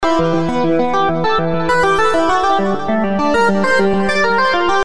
衣服移动弗利
描述：衣服运动。
标签： 敷料 FIBRA 服装 服饰 运动 ROPA 寻找 影视 运动 材料 moviendo 撕裂 buscando ropas 织物 片材 流泪 纺织品 纺织
声道立体声